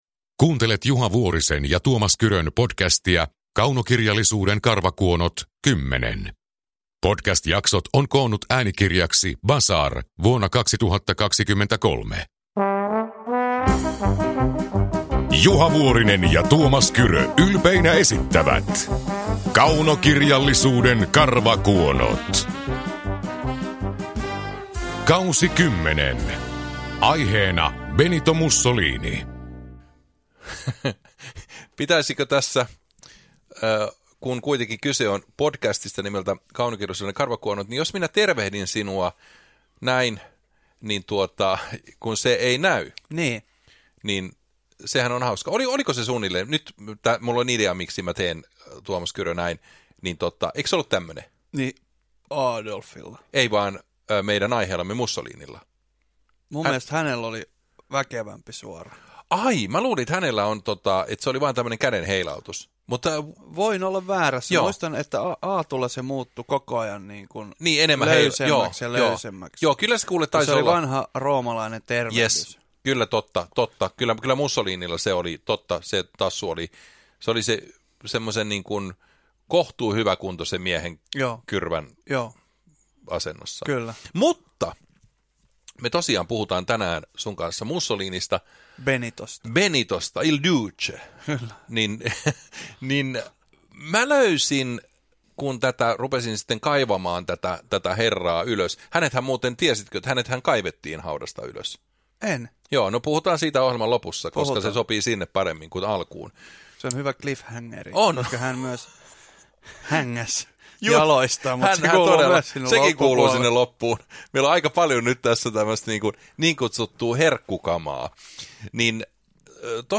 Kaunokirjallisuuden karvakuonot K10 – Ljudbok
Uppläsare: Tuomas Kyrö, Juha Vuorinen